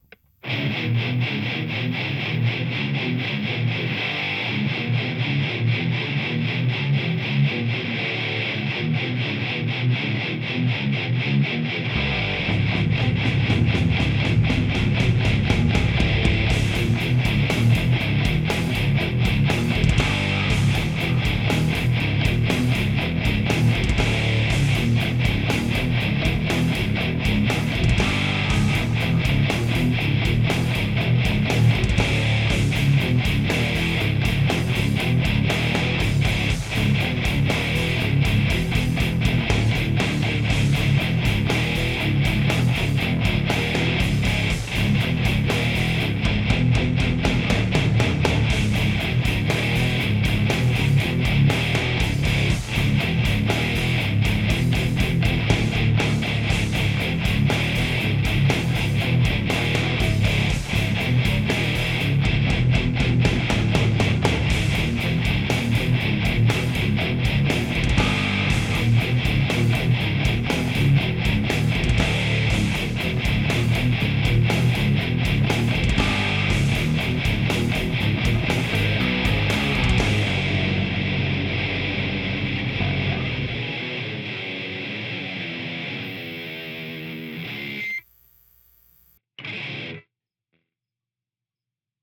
That little bit of verb on the drums gave me the :eek: and then :love:
Tbh perfect sound track for boss fight against the hat man at the end of the benedryl trip :rawk:
I like the tone you're getting with the DSL.